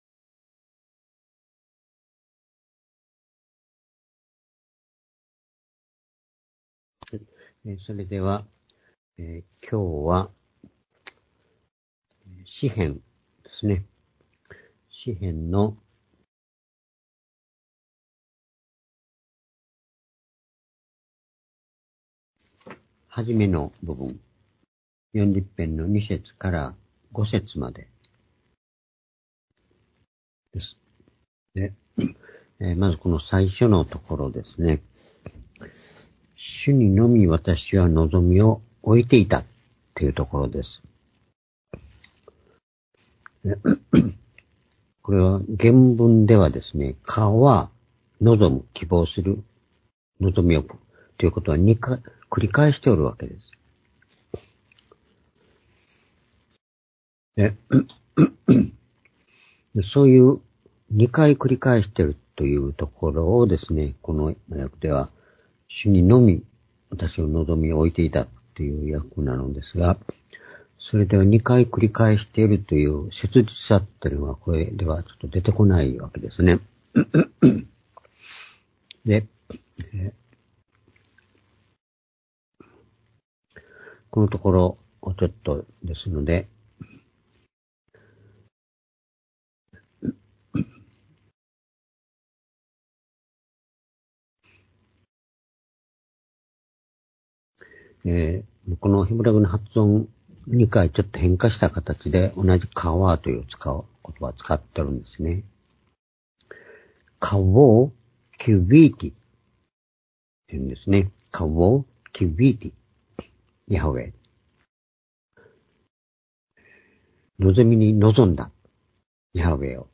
（主日・夕拝）礼拝日時 ２２0４年1月１６日（夕拝） 聖書講話箇所 「新しい讃美の生まれるとき」 詩編40の2-5 ※視聴できない場合は をクリックしてください。